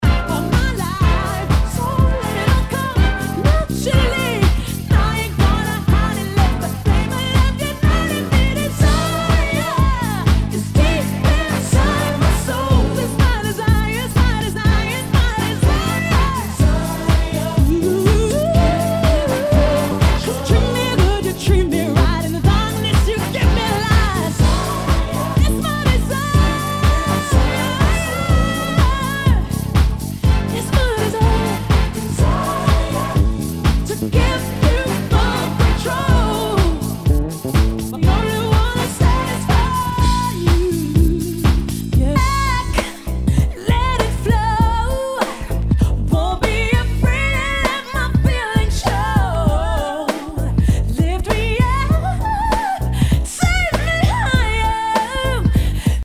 HOUSE/TECHNO/ELECTRO
ナイス！ヴォーカル・ハウス / R&B！